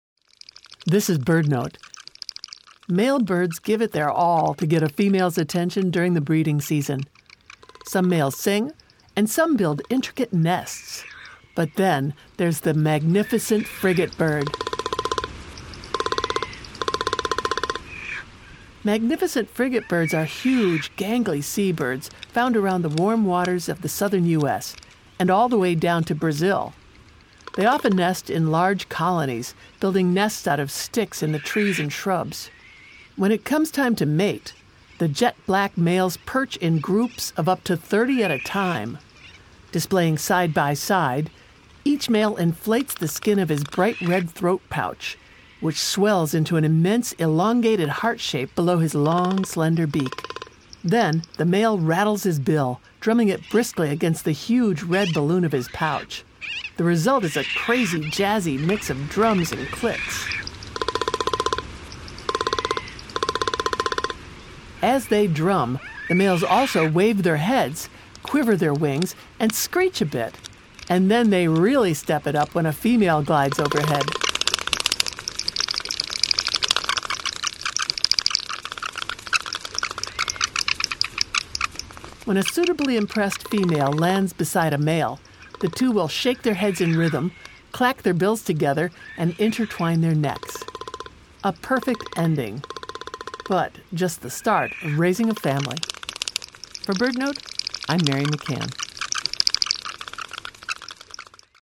Magnificent Frigatebird Drum Roll (Bird Note: 01/23/26) – Chirp Nature Center
Magnificent Frigatebirds are huge, gangly seabirds found around the warm waters of the Western Hemisphere. When it comes time to mate, males inflate giant red throat sacs, then rattle and drum their bills against them to create jazzy percussive sounds.